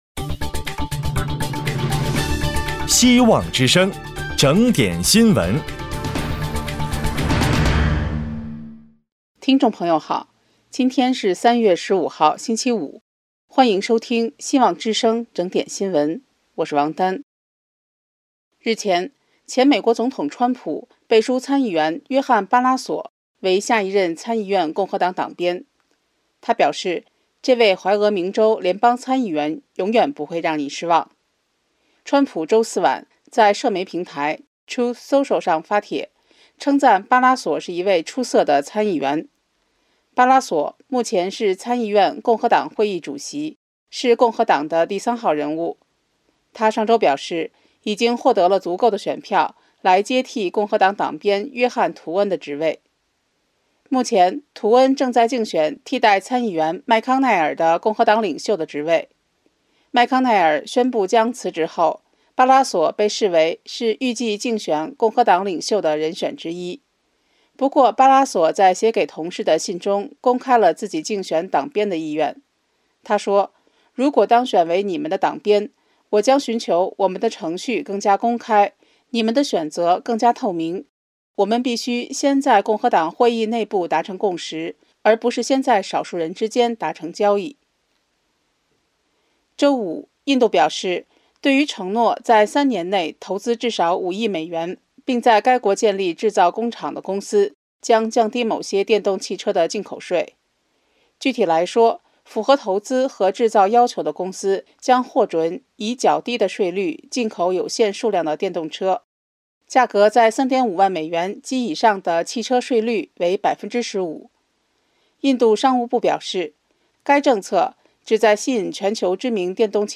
【希望之聲2024年3月15日】（配音：